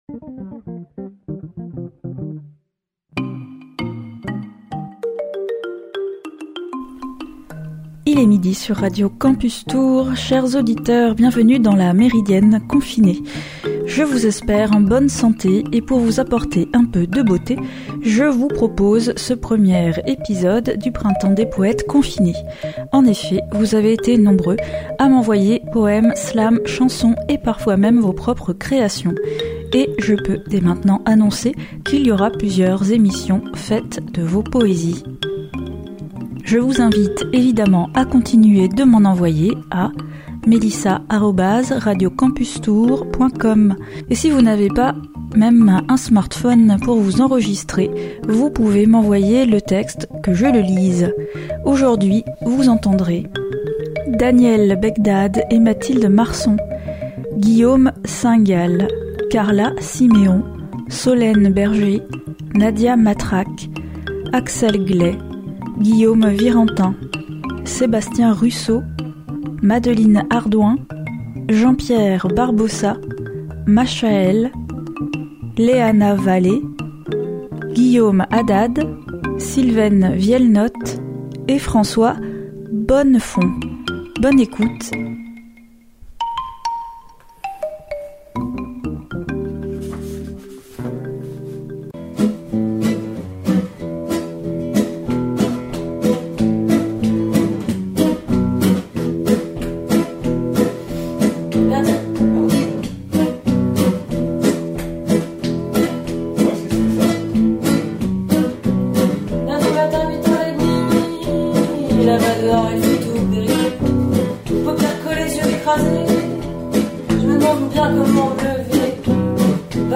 texte et guitare
interprétation et chant